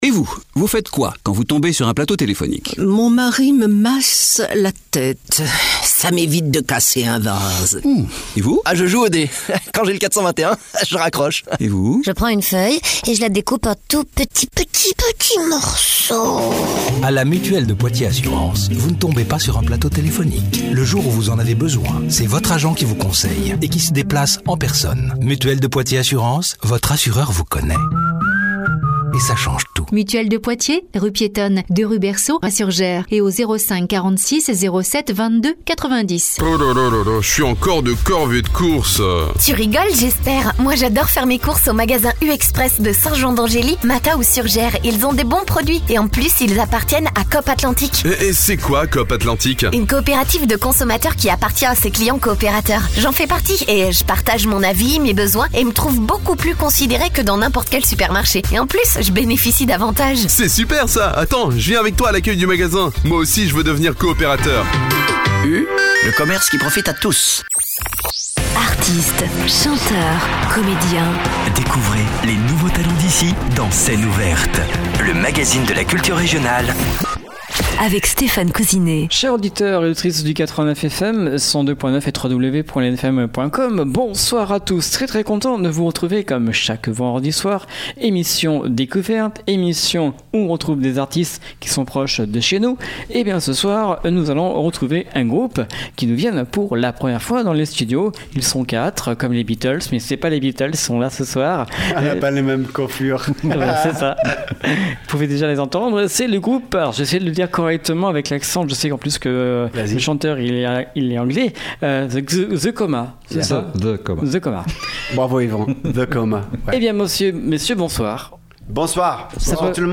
batterie
basse
guitare rythmique / chant
lead guitare / backing vocals